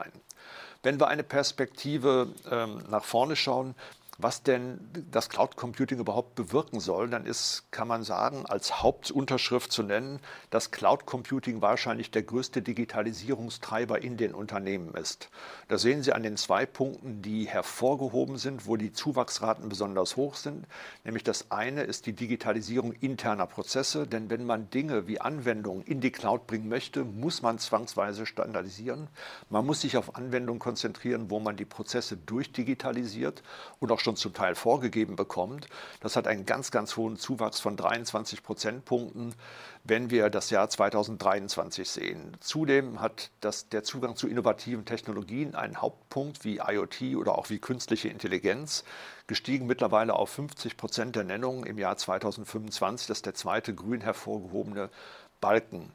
Mitschnitte der Pressekonferenz
bitkom-pressekonferenz-cloud-report-2025-audio-mitschnitt-digitalisierungstreiber.mp3